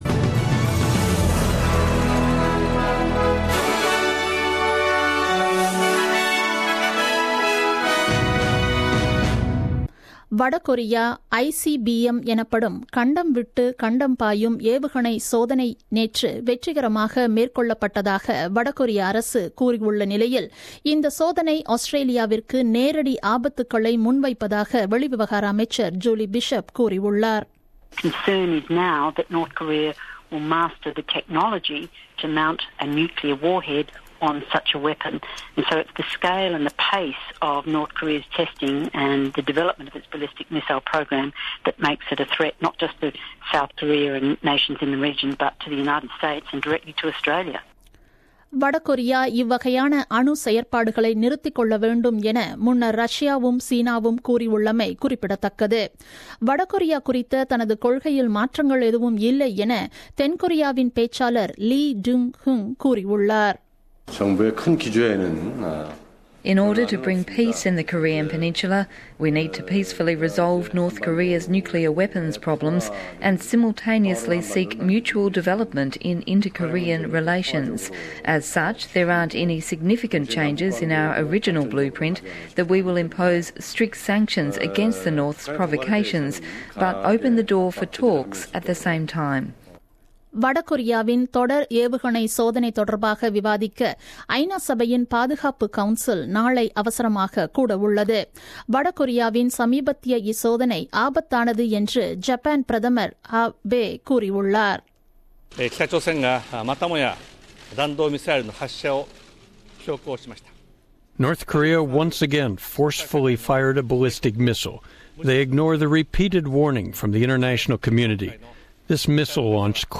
The news bulletin broadcasted on 5th July 2017 at 8pm.